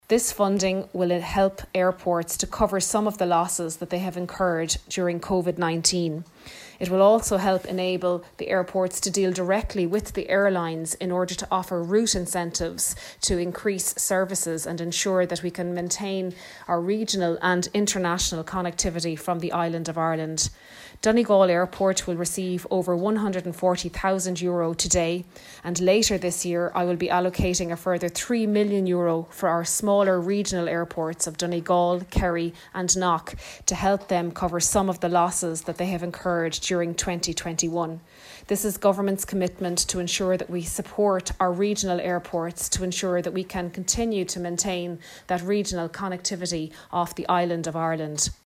Minister of State at the Department of Transport, Hildegarde Naughton says the funding will go towards helping the facilities get back up and running again post-Covid: